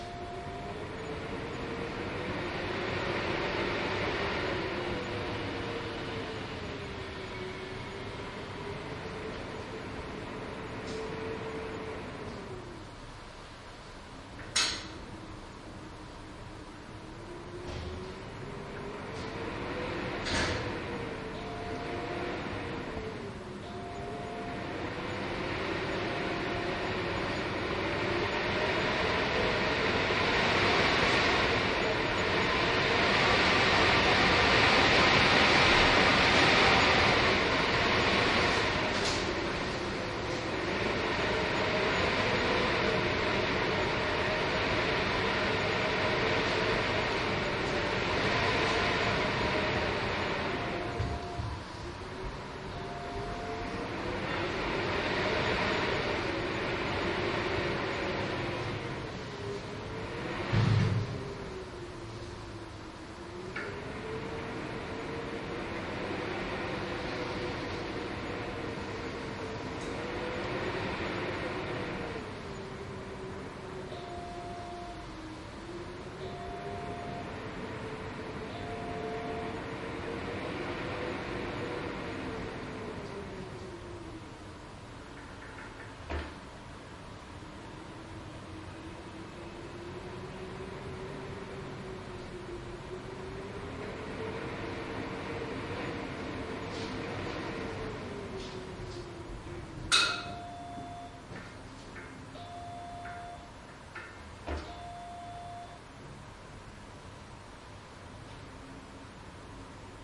博物馆电梯井的风
描述：在暴风雨的一天，傍晚，博物馆电梯井记录了风声。
Tag: 风暴 建筑 风能 升降杆 升降 电梯